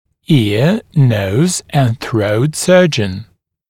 [ɪə nəuz ənd θrəut ‘sɜːʤ(ə)n][иа ноуз энд сроут ‘сё:дж(э)н]хирург-оториноларинголог